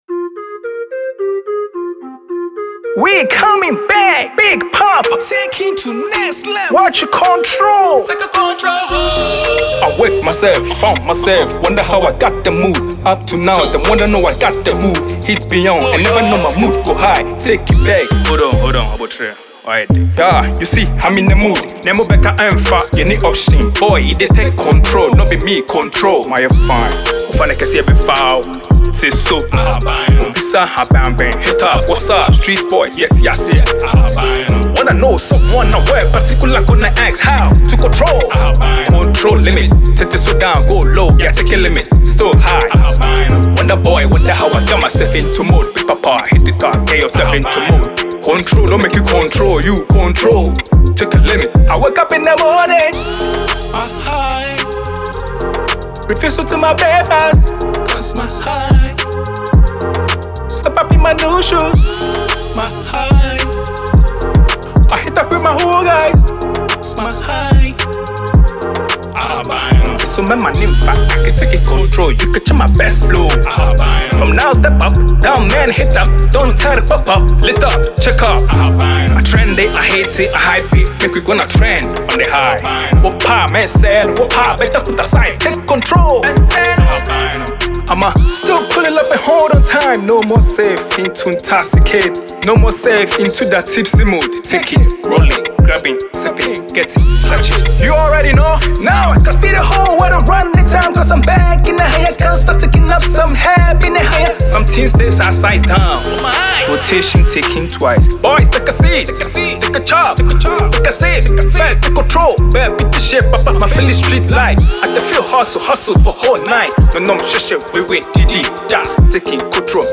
Afrobeat and highlife music
smooth vocals and emotional tone
With a well-produced beat and catchy hook